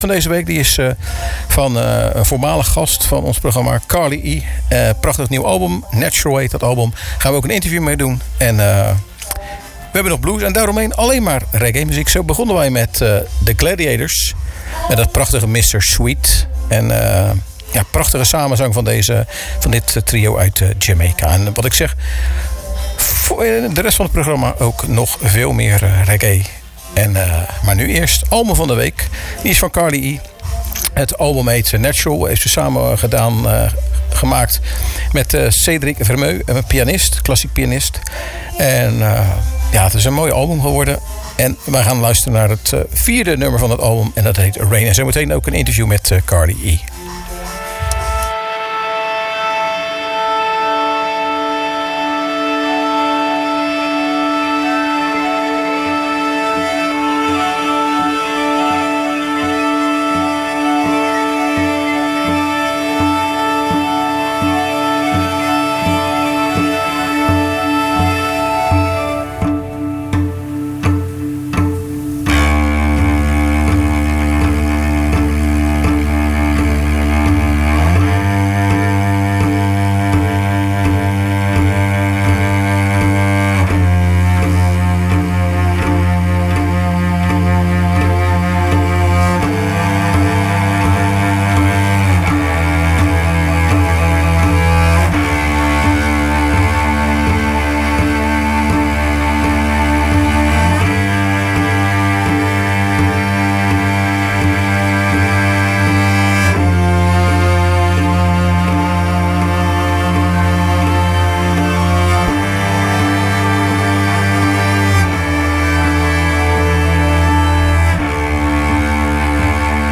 Cello singer-songwriter